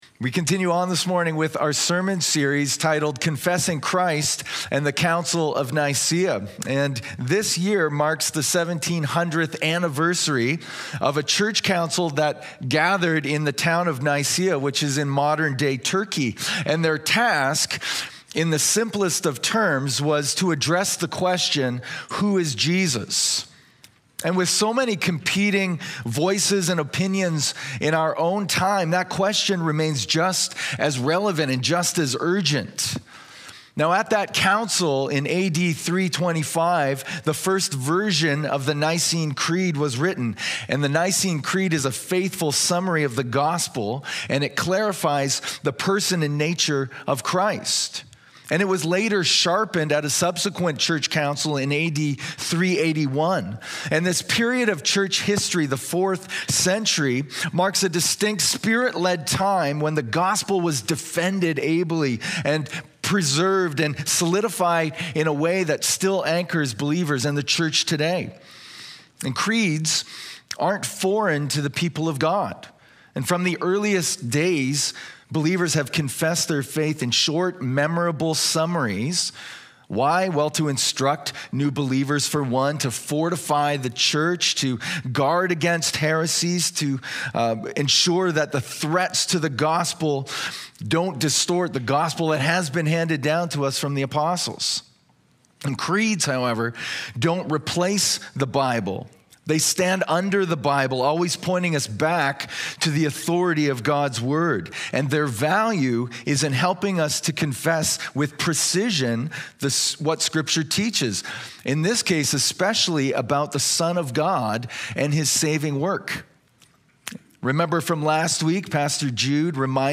A Podcast of West London Alliance Church featuring the latest Sunday morning sermon.